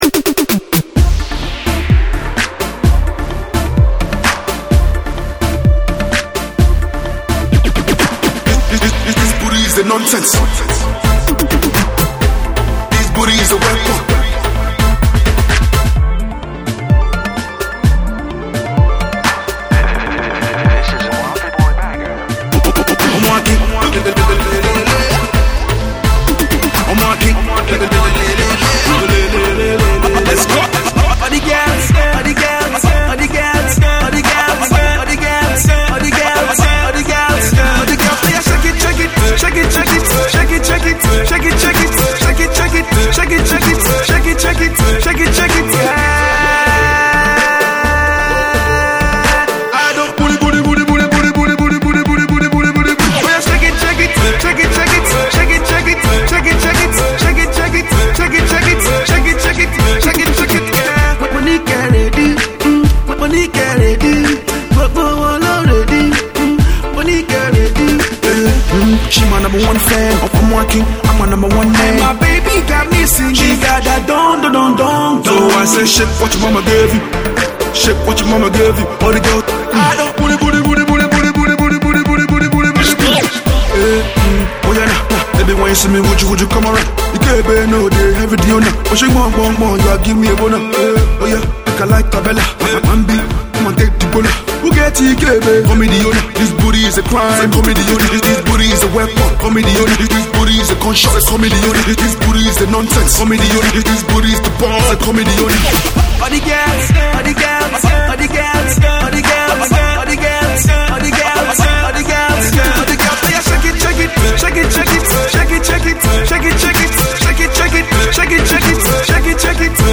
club banging single